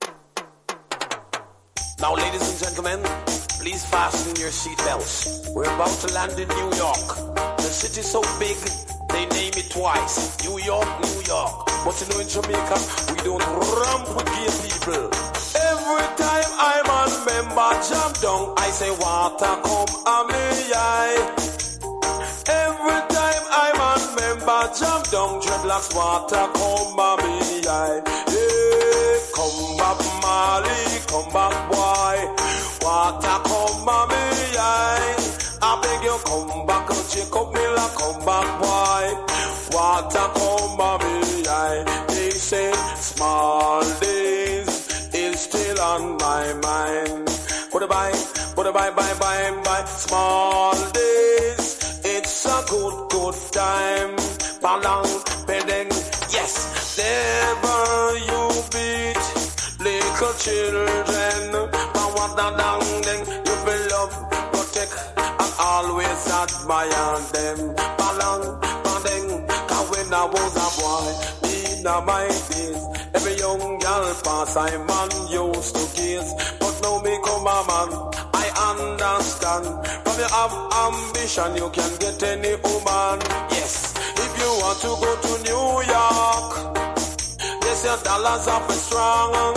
所によりノイズありますが、リスニング用としては問題く、中古盤として標準的なコンディション。